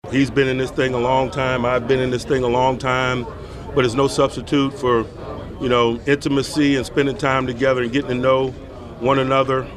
At the NFL’s annual meetings yesterday, Mike Tomlin said he enjoyed spending time with Rodgers at the Steelers’ facility two Fridays ago.